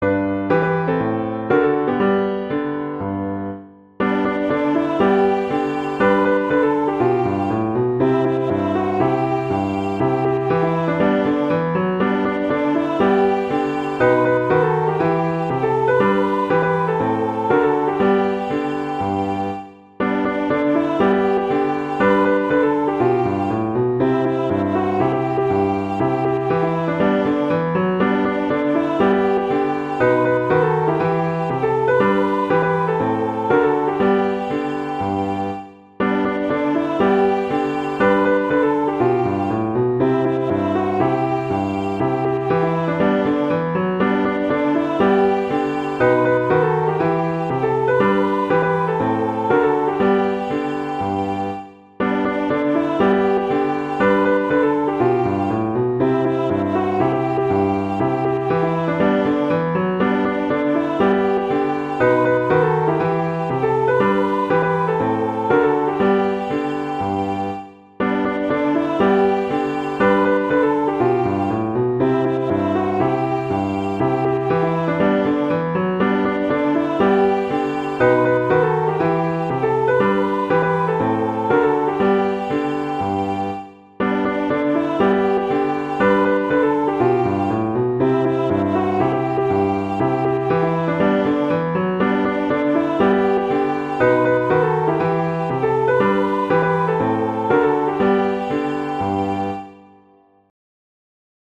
traditional, christian, inspirational, children
G major
♩=120 BPM